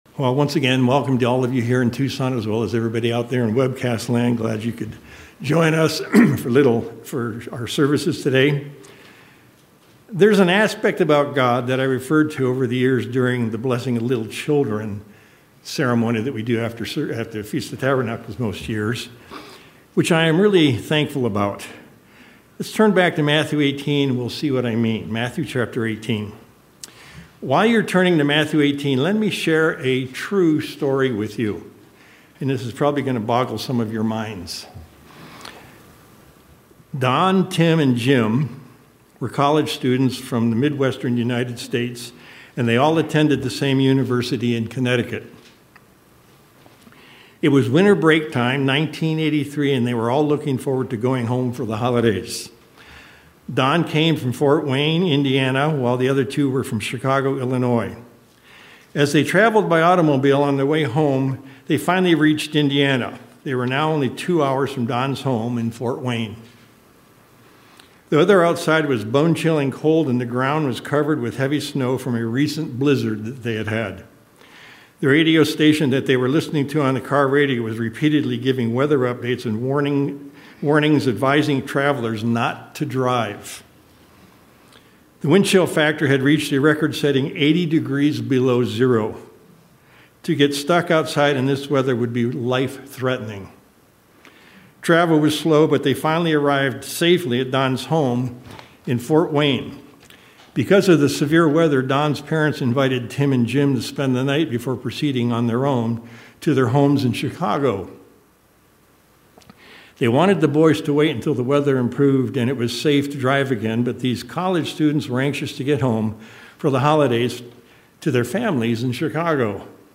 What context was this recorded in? Given in Tucson, AZ El Paso, TX